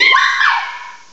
cry_not_mimikyu.aif